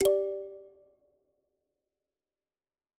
power-plug.wav